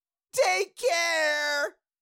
Cartoon Little Monster, Voice, Take Care Sound Effect Download | Gfx Sounds
Cartoon-little-monster-voice-take-care.mp3